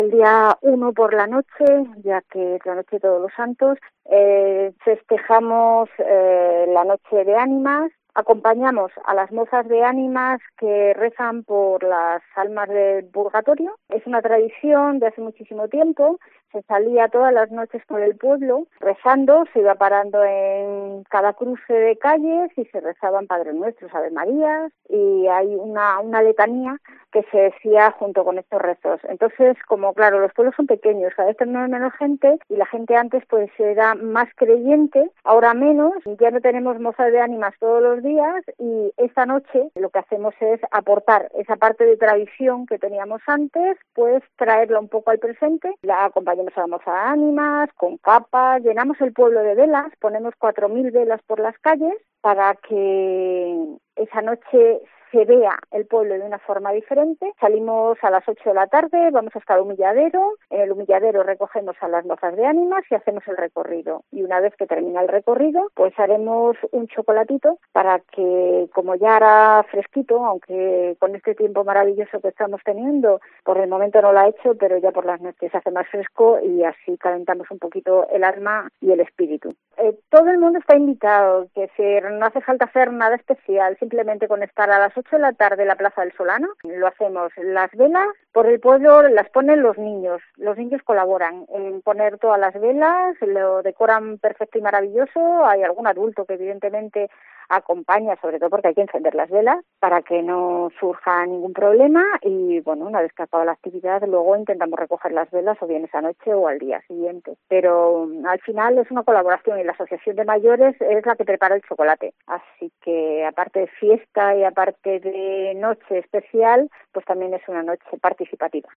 La alcaldesa de Mogarraz Soledad Alvarez, nos invita a participar en este acto el lunes a las 20:00h